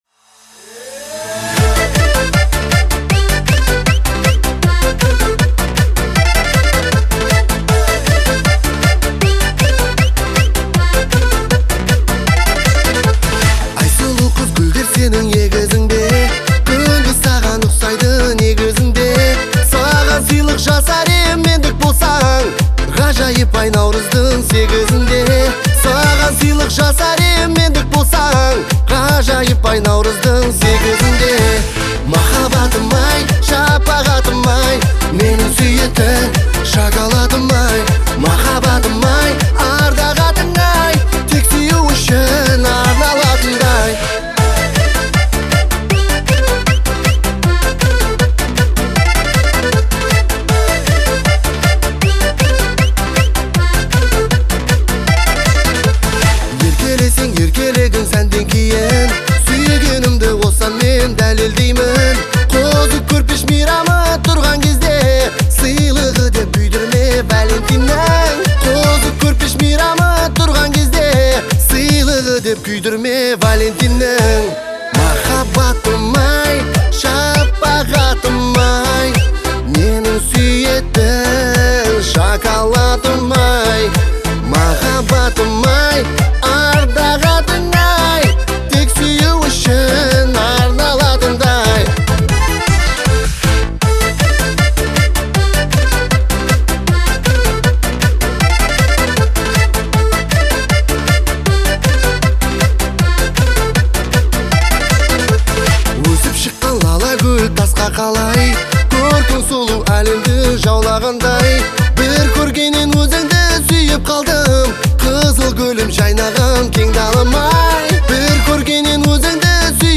яркая и зажигательная песня